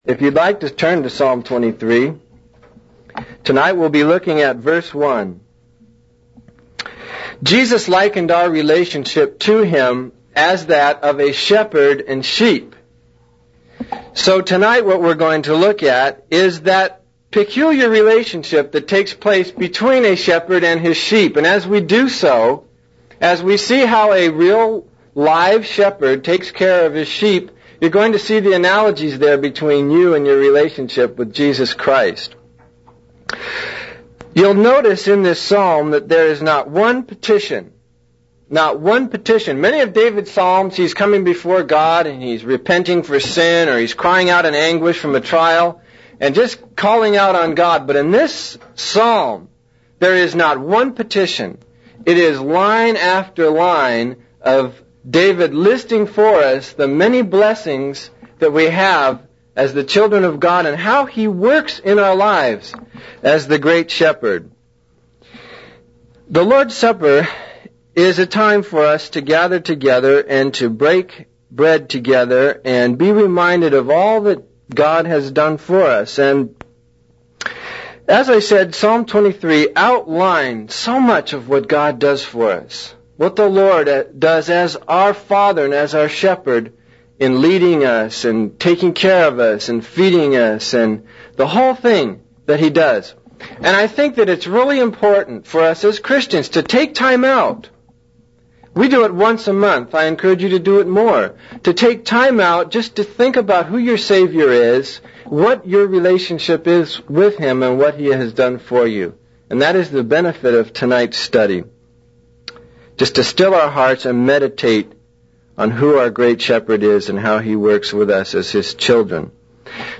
In this sermon, the preacher discusses the temptation of the devil and how he tries to deceive us with enticing offers. He compares the devil's tactics to a lush green pasture that looks appealing but ultimately leads to disappointment and emptiness. The preacher emphasizes the importance of following the good shepherd, who leads us to true satisfaction and contentment.